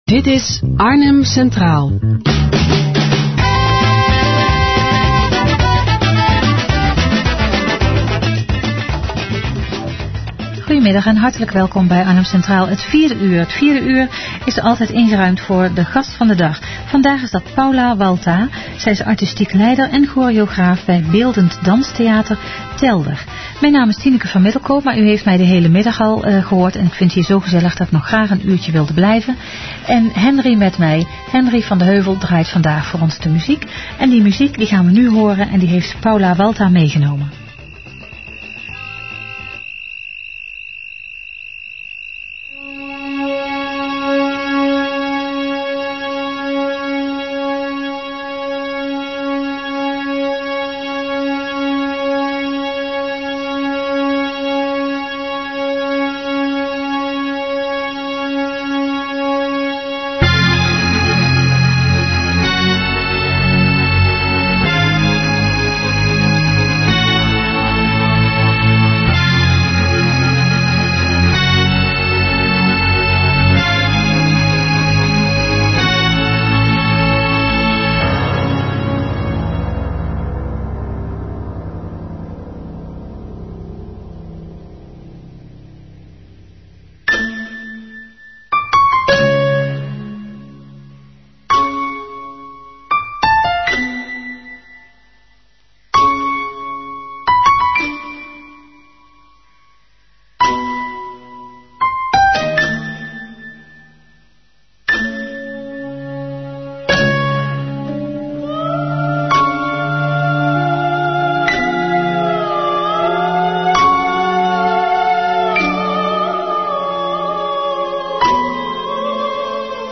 RadioUitzending_ACdinsdag2-bijgesneden.mp3